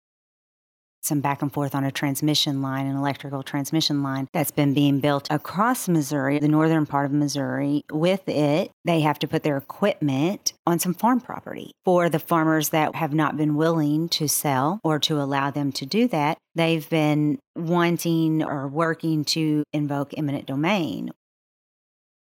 3. Senator Thompson Rehder also says House Bill 2005 is legislation that relates to eminent domain.